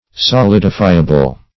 Solidifiable \So*lid"i*fi`a*ble\, a. Capable of being solidified.